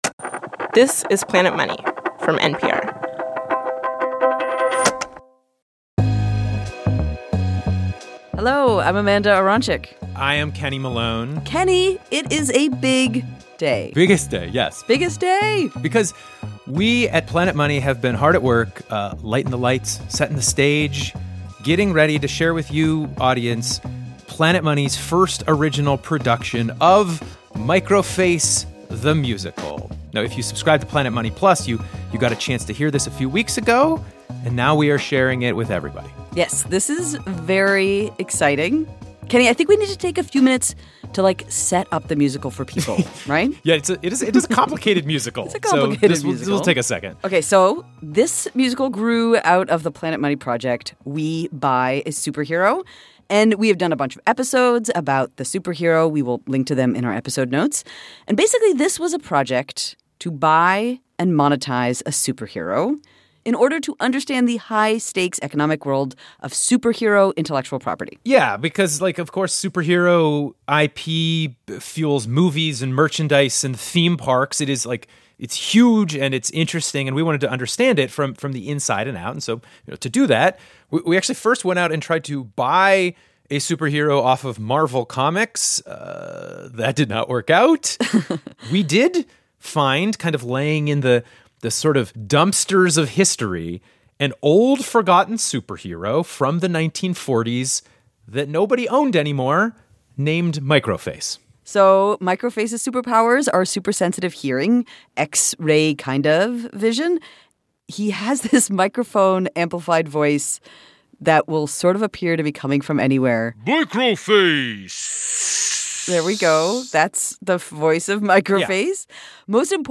A full concert recording